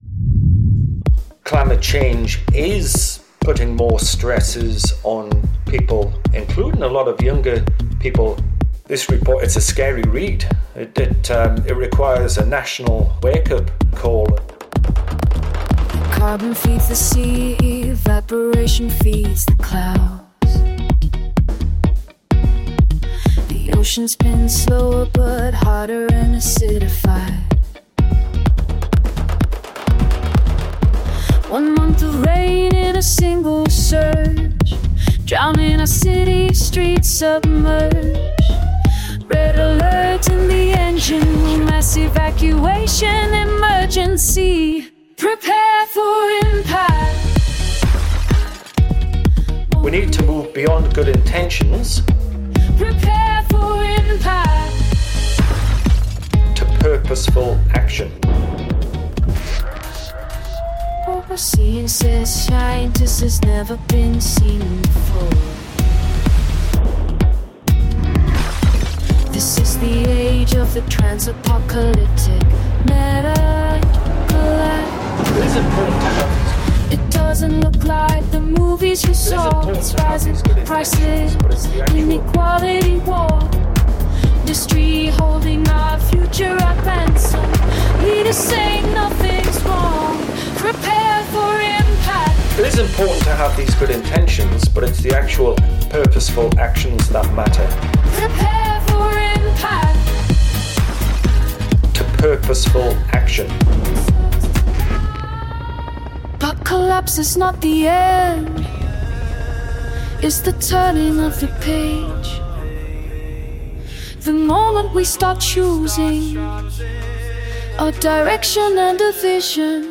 Blending poetic urgency with scientific truth
danceable call for decisive, purposeful climate action